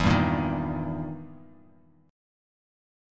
EmM7b5 chord